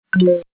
aya/client/common/content/sounds/button.mp3 at main
button.mp3